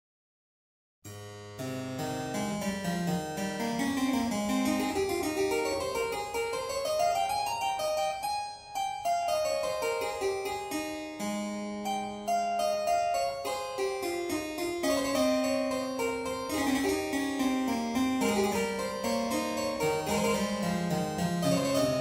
Клавесин!